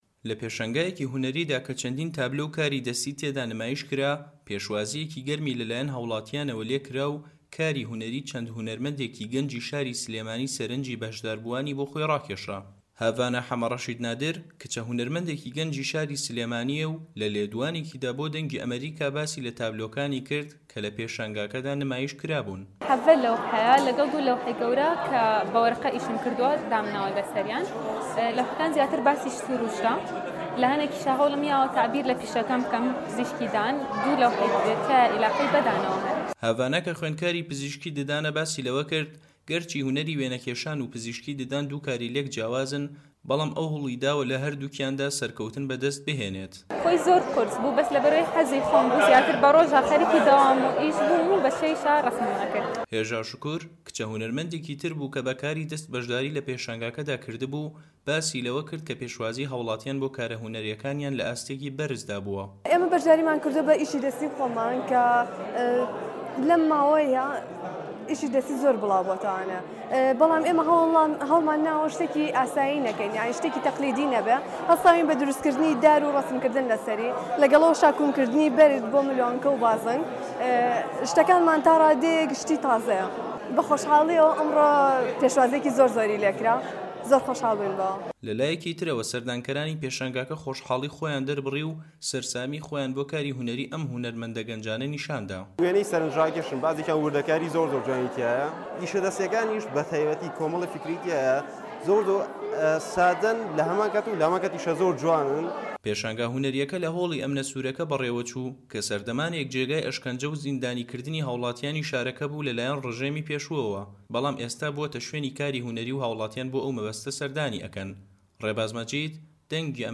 ڕاپۆرتی پەیامنێری دەنگی ئەمەریکا